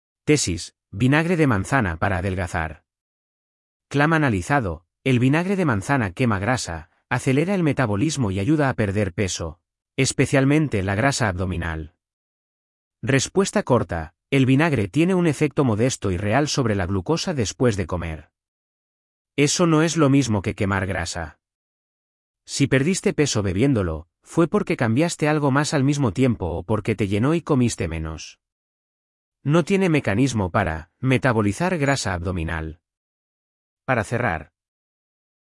Voz: Microsoft Alvaro (es-ES, neural).